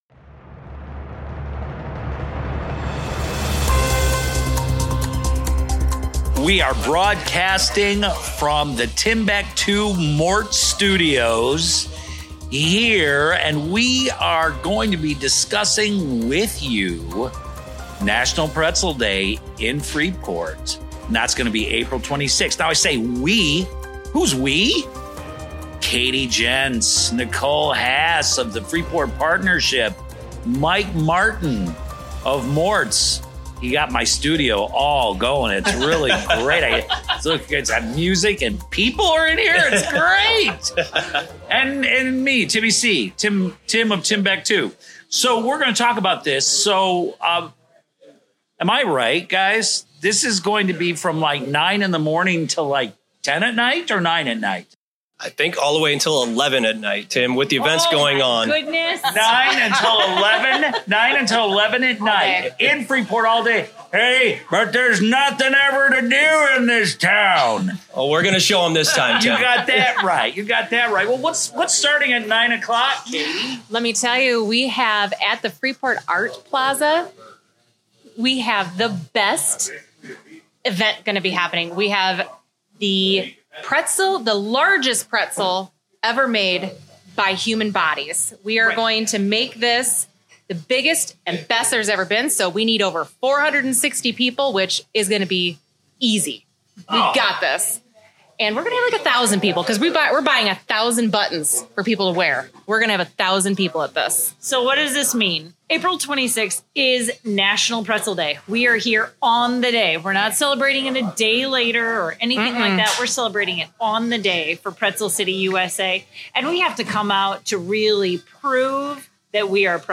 Freepod - Freepod Interview: Pretzel Fest and Polka Fest